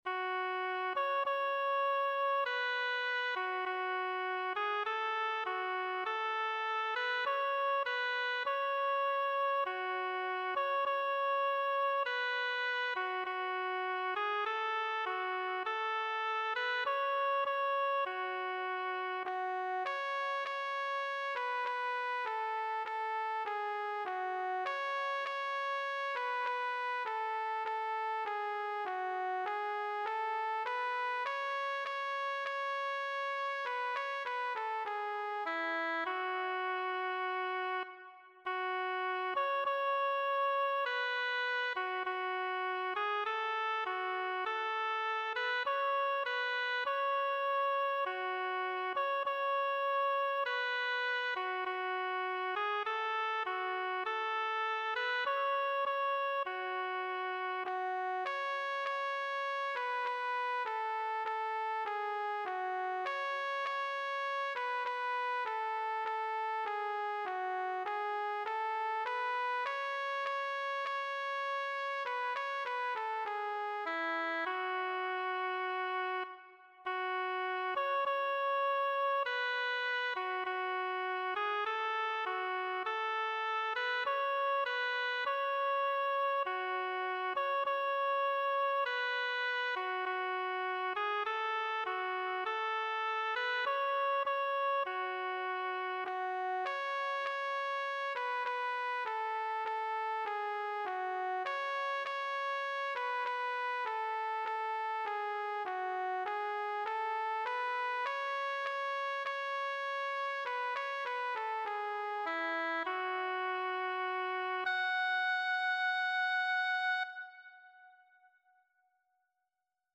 Abraham-y-El-Yo-Soy-LaM.mp3